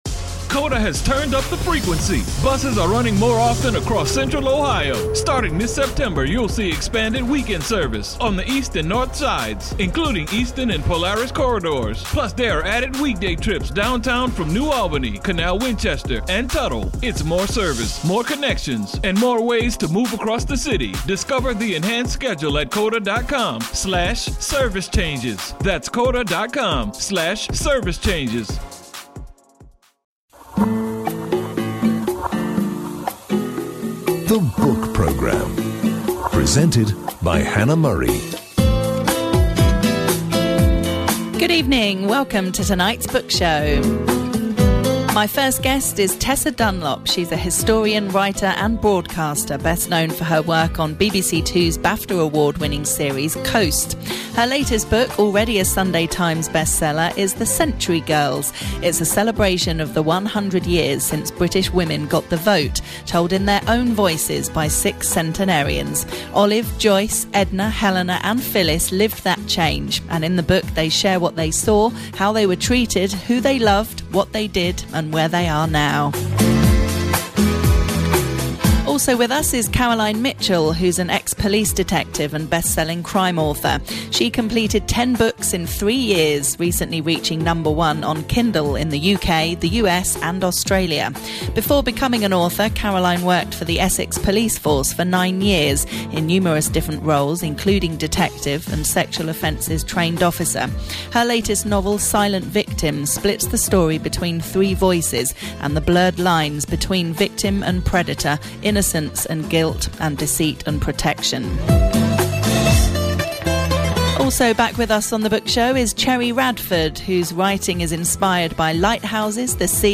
catches up top authors, to discuss their latest releases